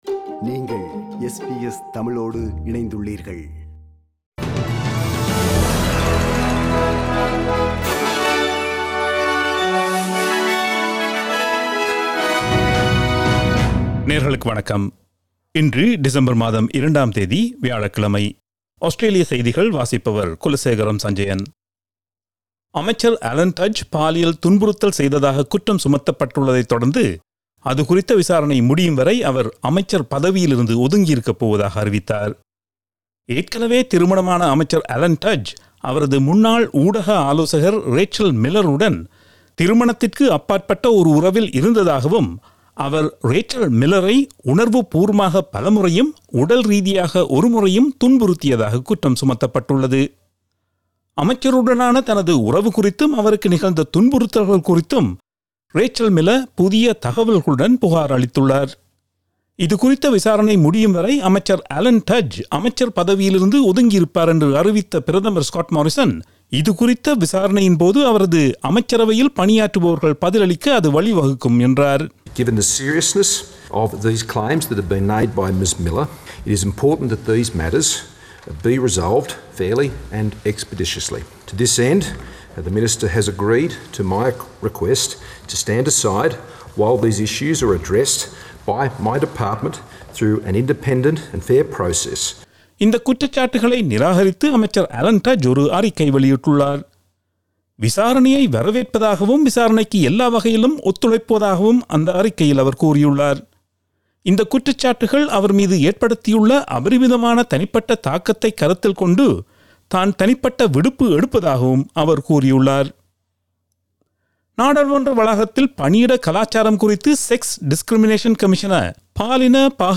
Australian news bulletin for Thursday 02 December 2021.